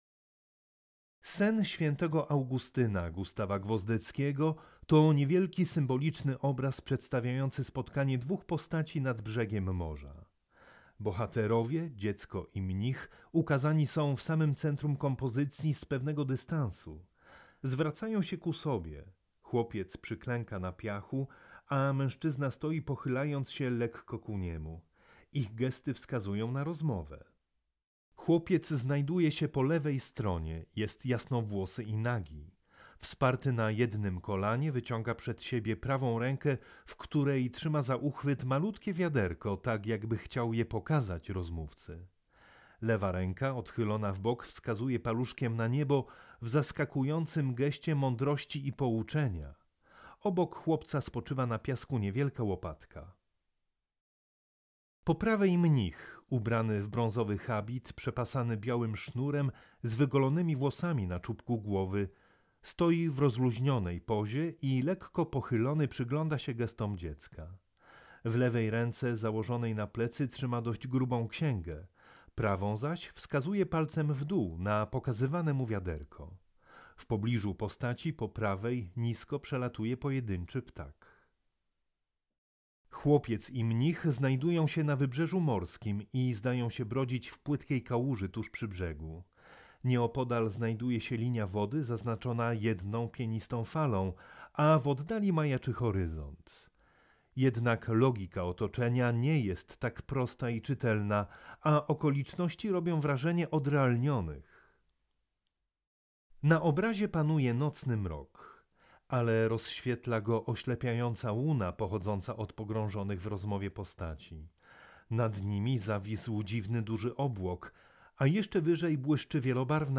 AUDIODESKRYPCJA-Gustaw-Gwozdecki-Sen-sw.-Augustyna.mp3